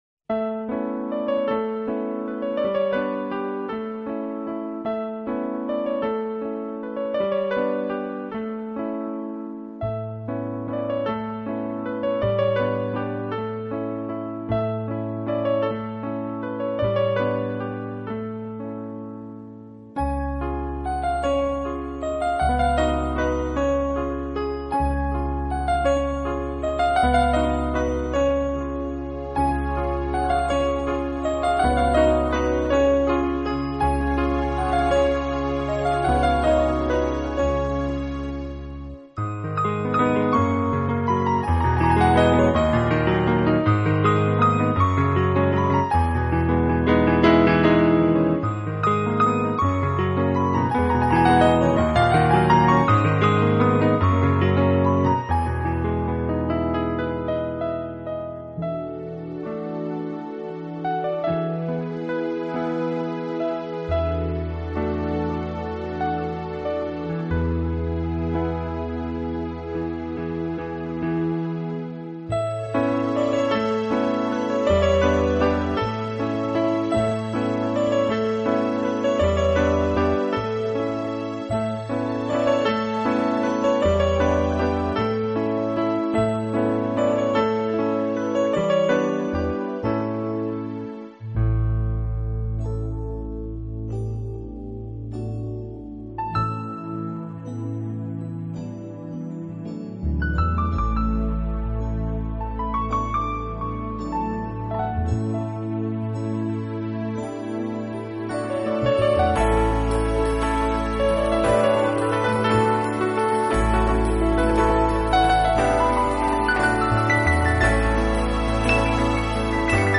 专辑语言 纯音乐